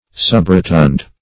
Subrotund \Sub`ro*tund"\, a. Somewhat rotund.
subrotund.mp3